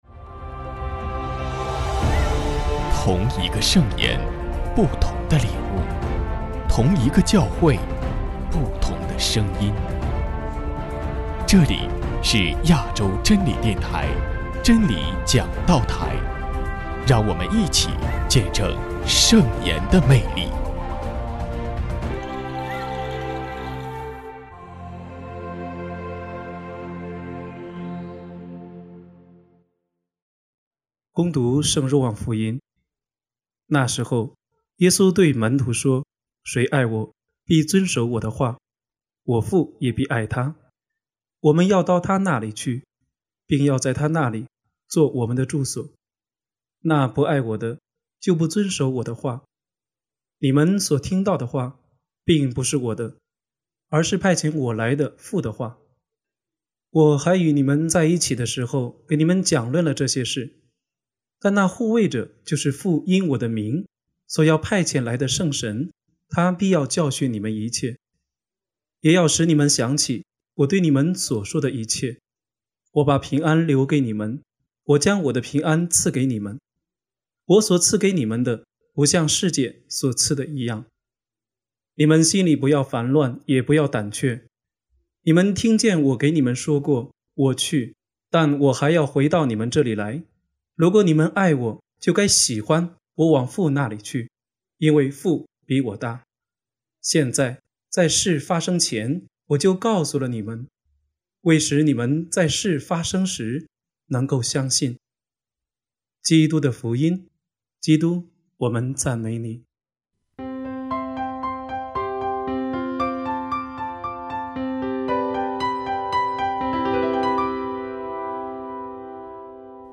【真理讲道台 】73|复活节第六主日证道